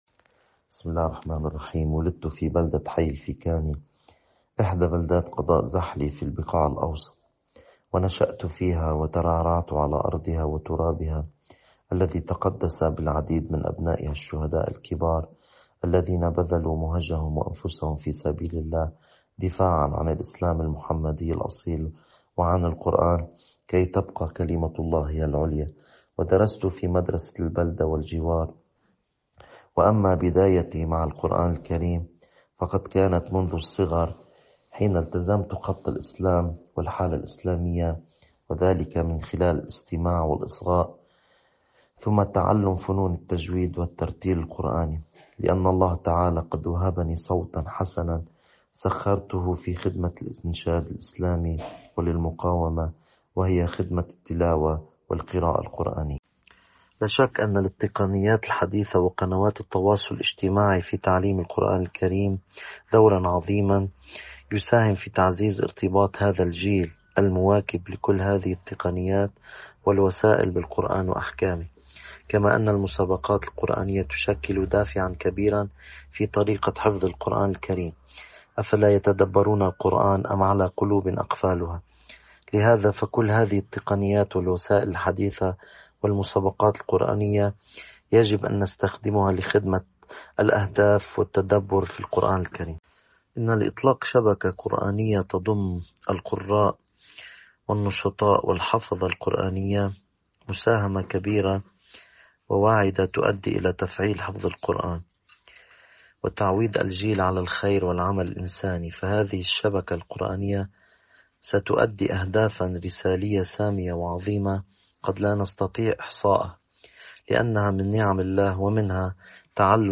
فیما یلي مقطع صوتي من مقابلة